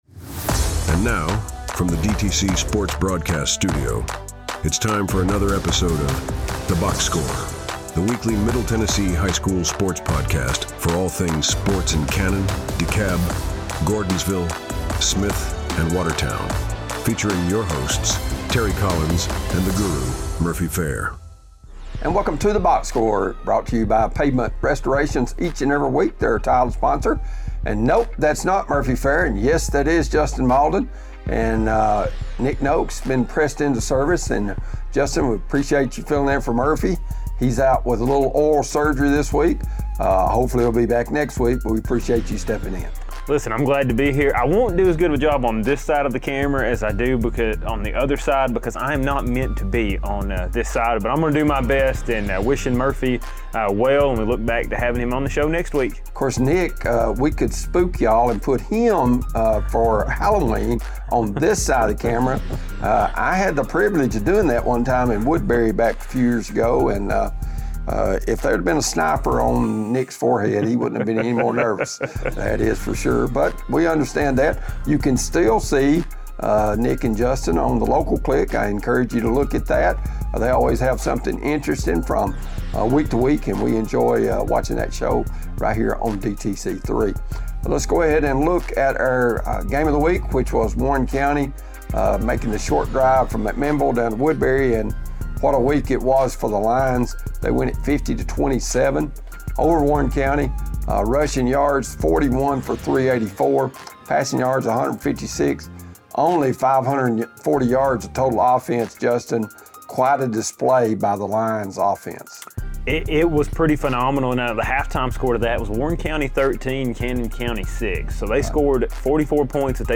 weekly sports talk show, including guest interviews